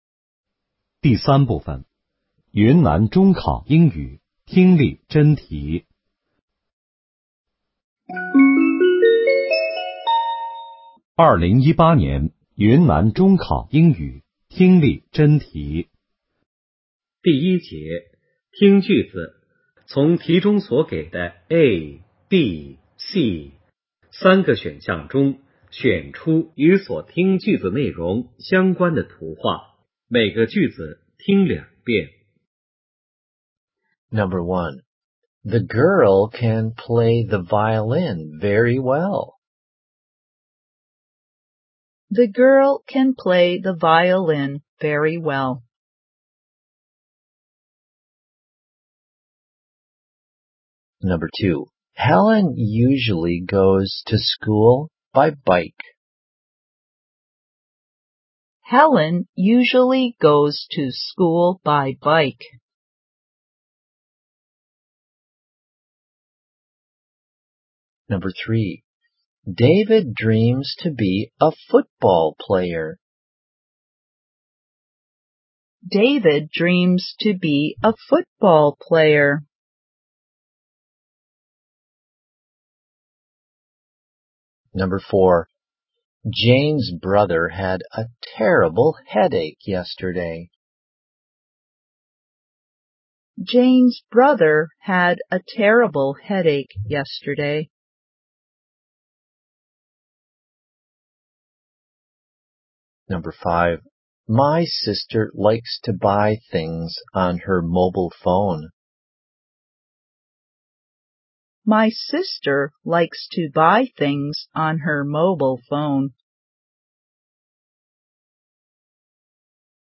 2018年云南中考英语听力：